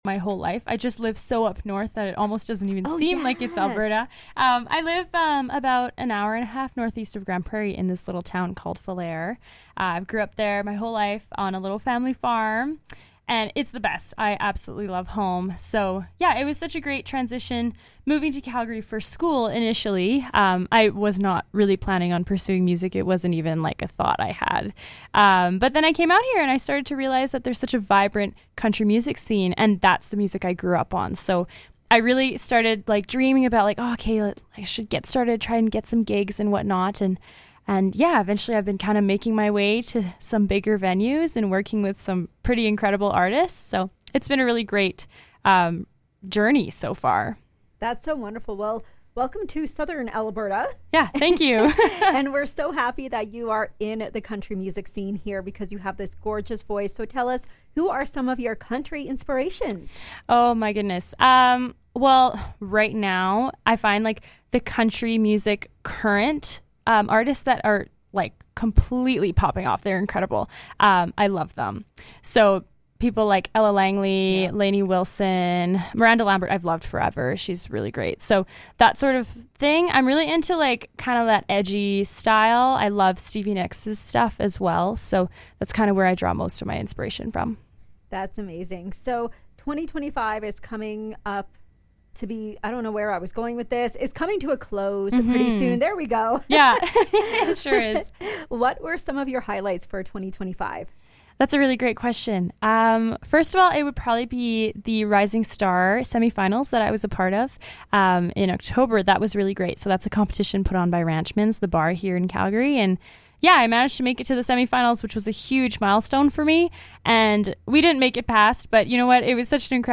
Here’s our conversation: https